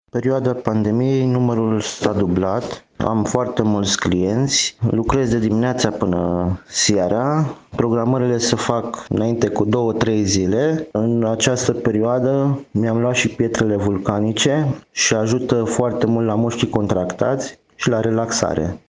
maseur